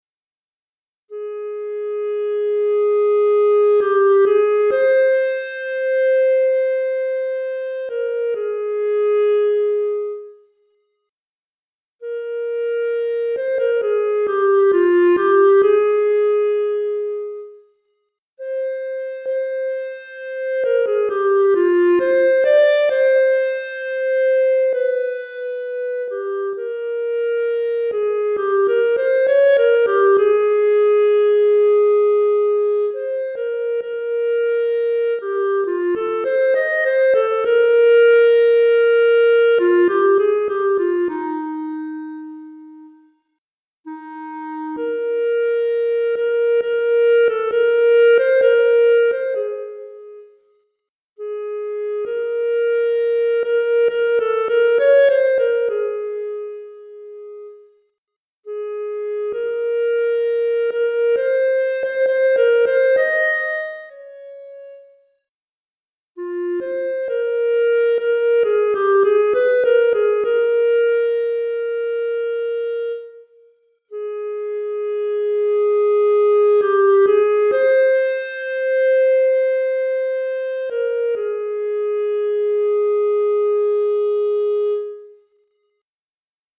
Clarinette Solo